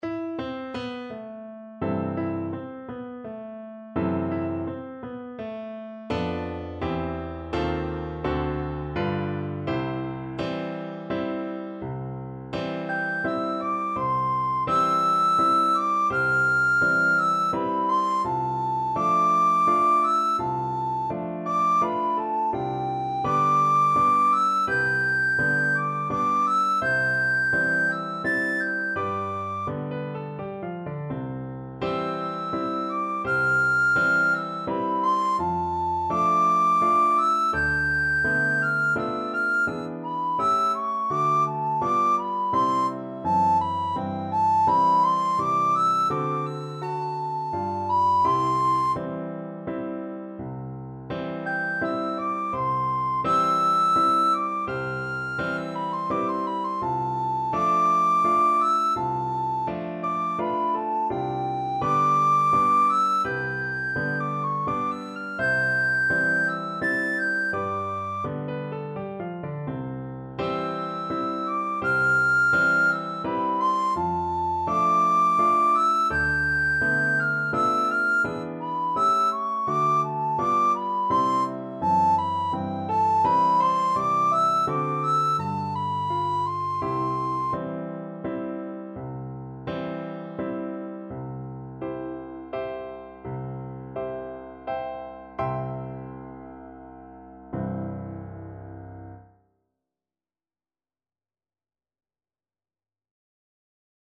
Free Sheet music for Soprano (Descant) Recorder
C major (Sounding Pitch) (View more C major Music for Recorder )
= 84 Andante non troppe e molto maestoso
3/4 (View more 3/4 Music)
Classical (View more Classical Recorder Music)